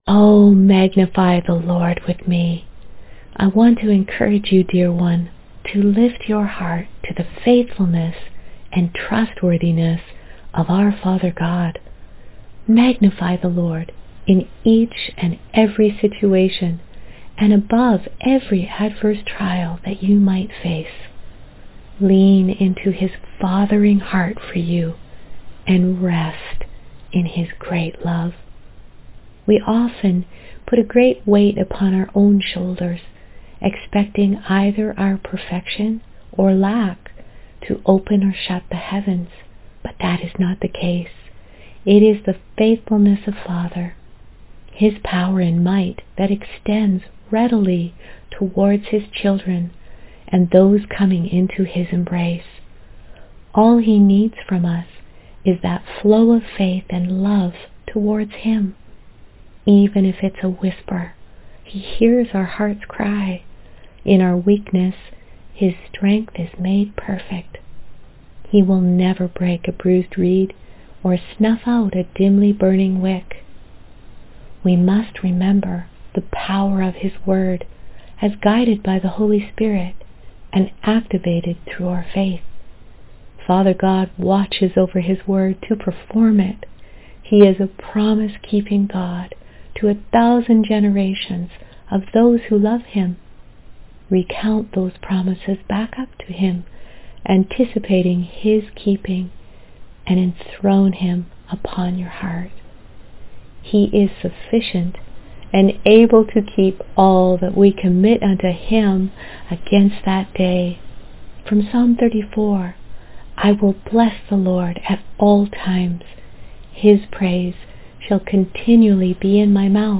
Prayer and Verse